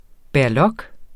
Udtale [ bæɐ̯ˈlʌg ]